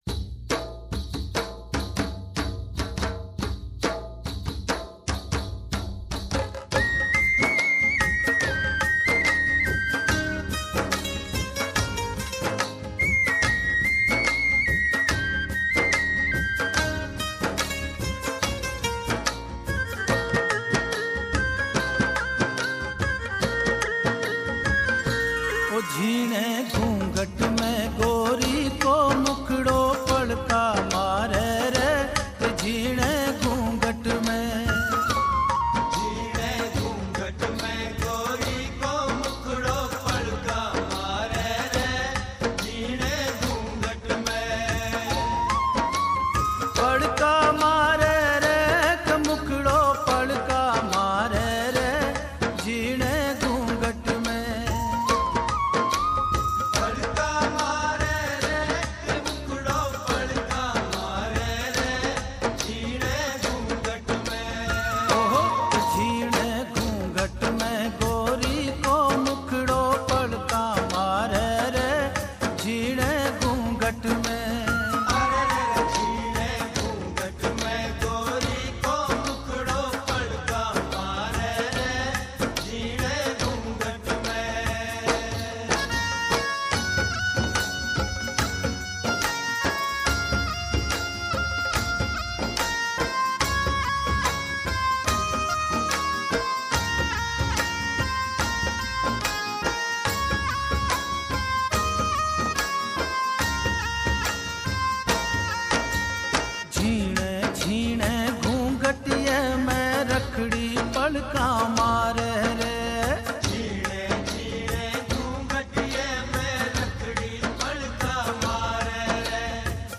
Rajasthani Songs